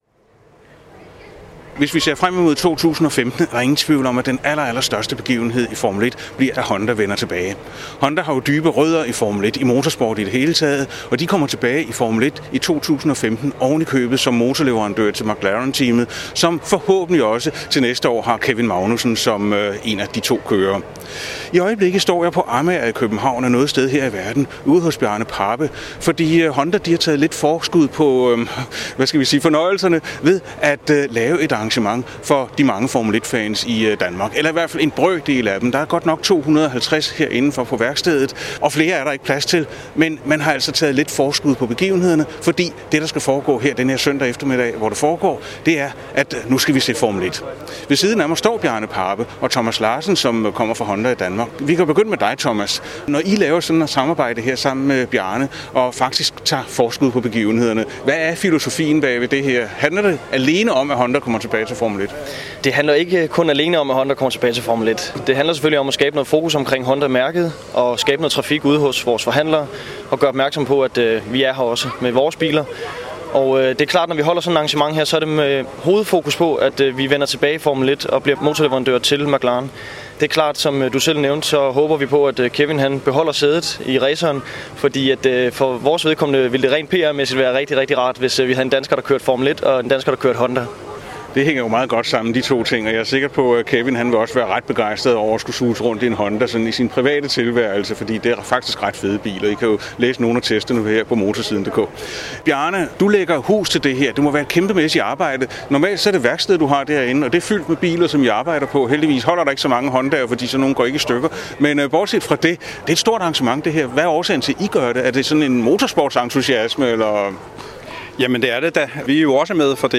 Honda inviterede til Formel 1 show
Interview